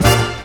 JAZZ STAB 33.wav